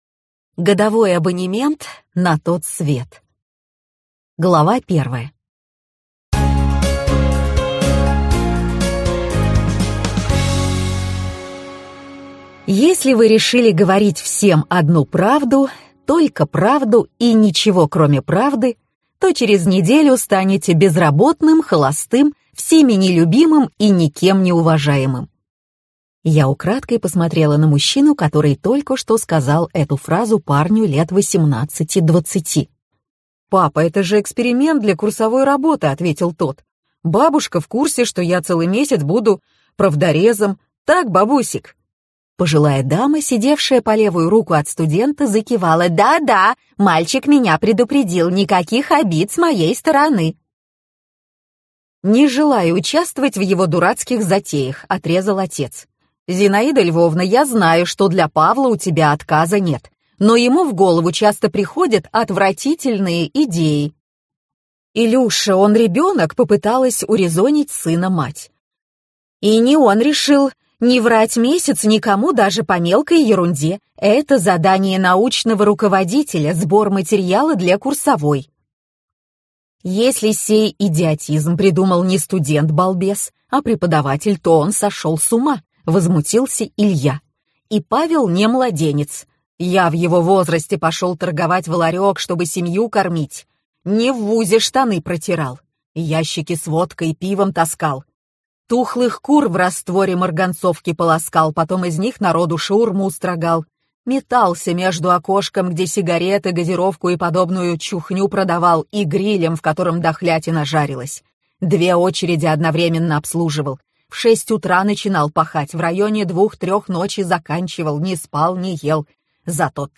Аудиокнига Годовой абонемент на тот свет - купить, скачать и слушать онлайн | КнигоПоиск